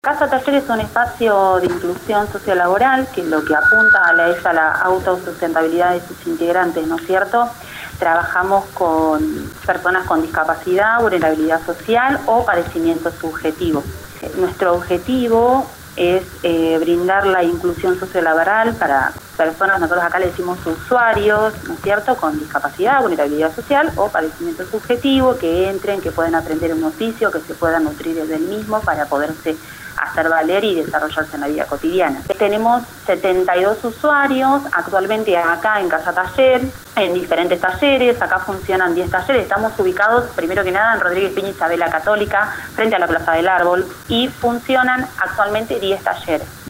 entrevistada por LU 24